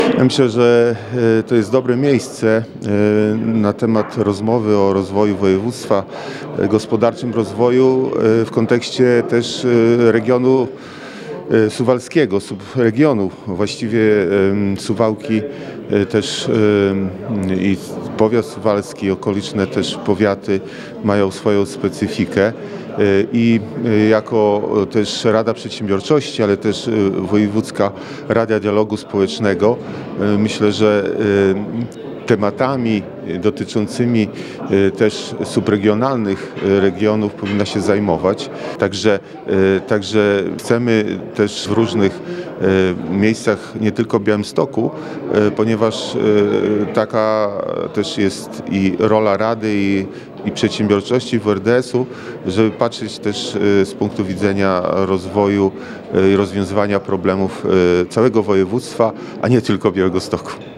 Posiedzenie plenarne Wojewódzkiej Rady Dialogu Społecznego odbyło się w środę (21.03) w Suwałkach.
marszałek.mp3